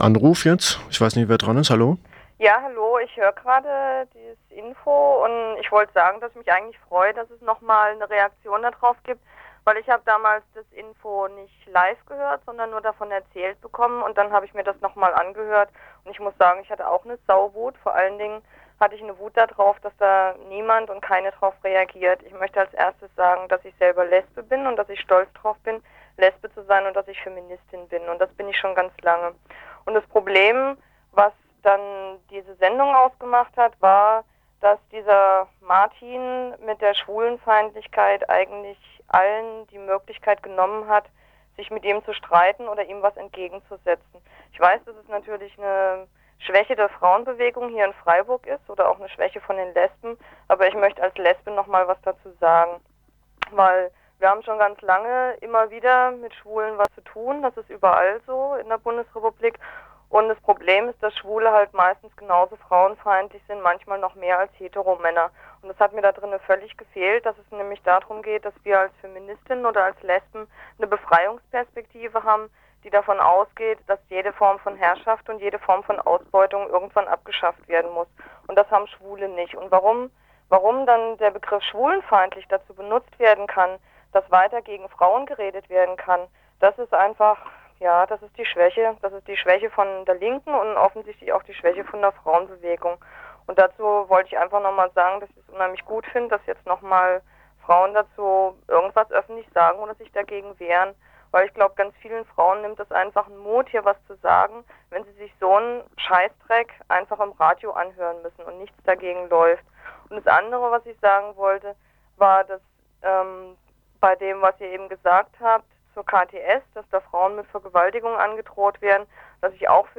Anrufe 1